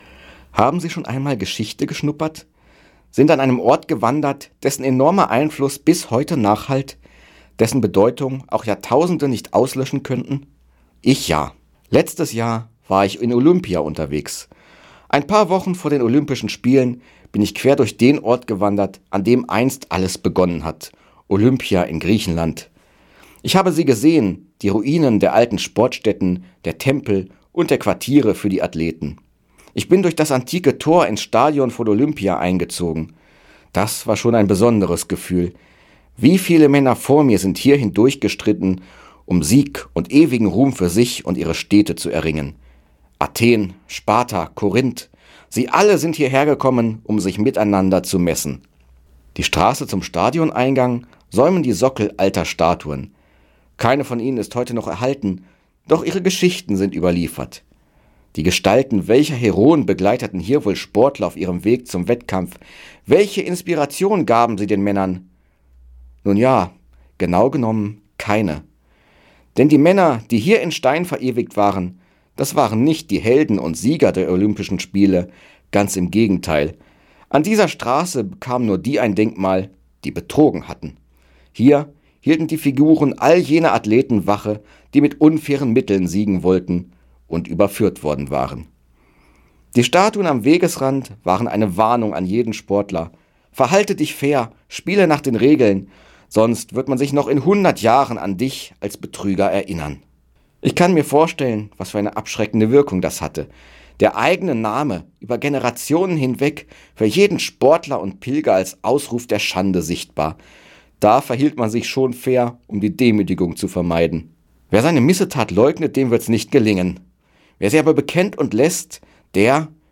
Radioandacht vom 26. Februar